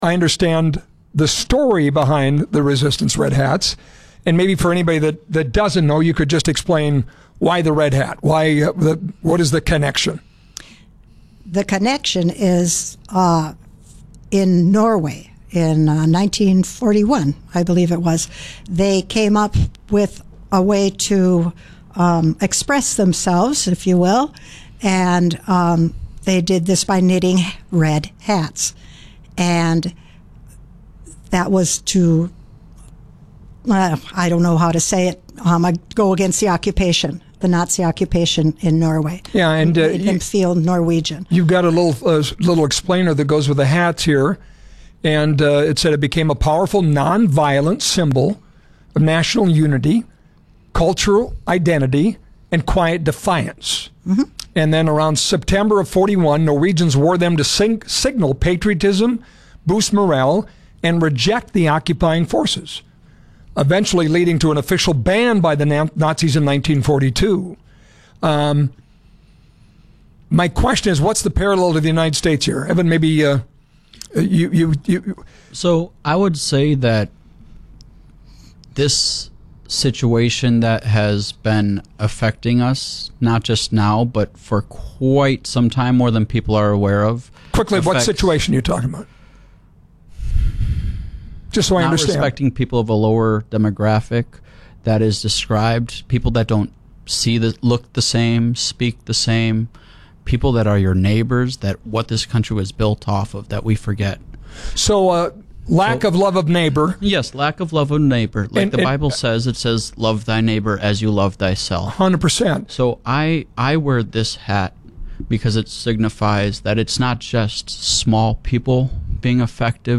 red-hats-interview.mp3